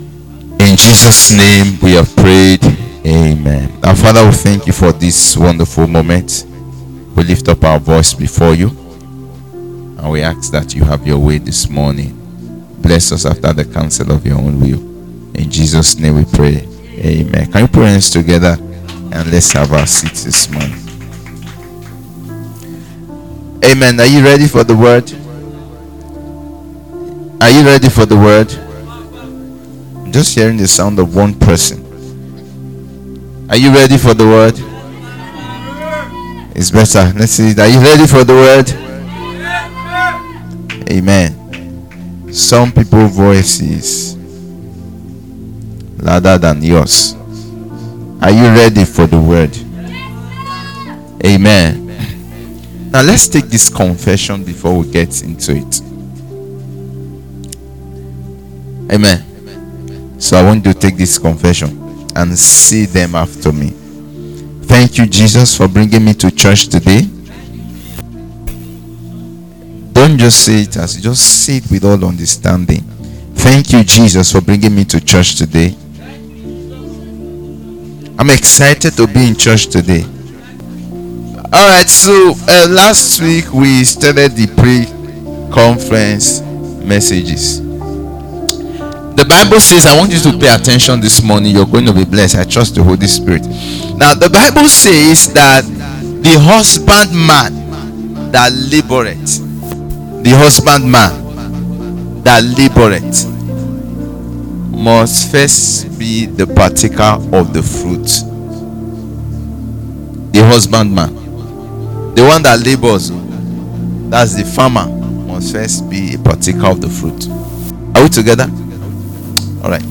Faith Service Type: Revelation Service This two-part series of exercising faith by speaking the word will bless you.